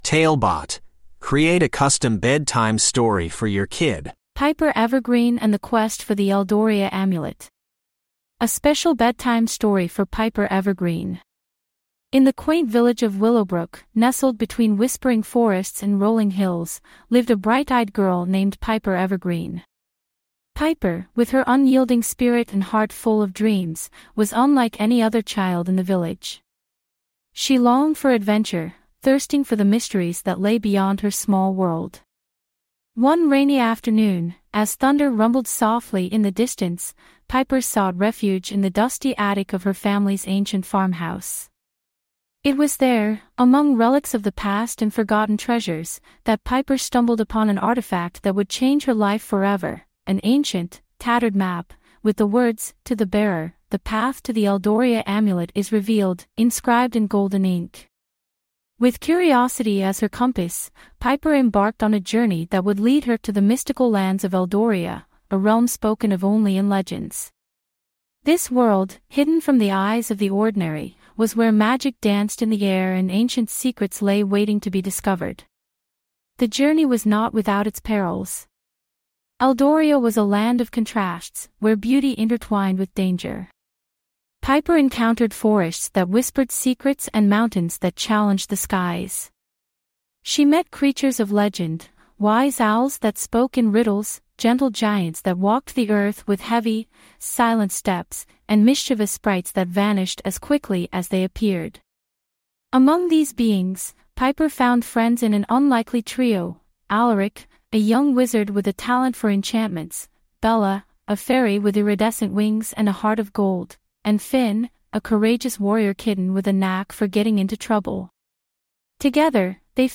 TaleBot Bedtime Stories
TaleBot AI Storyteller